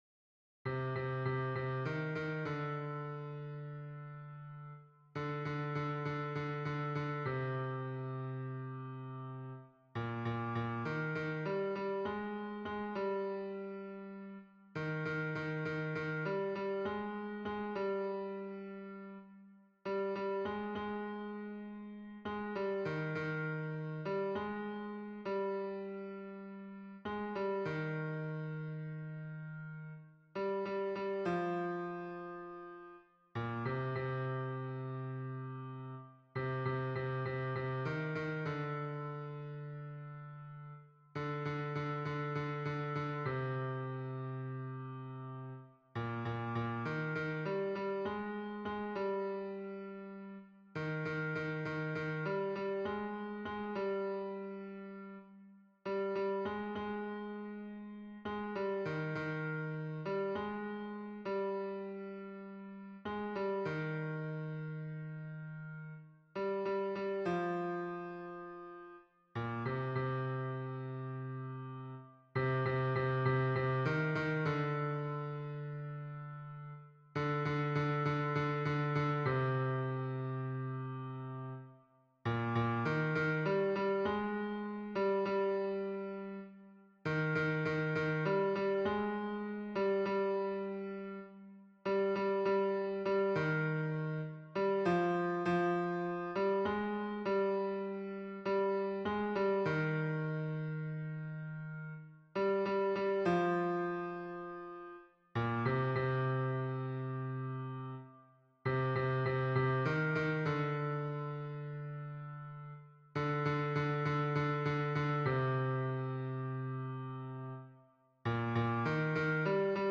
MP3 version piano - 3 voix (pour la chorale)
Hommes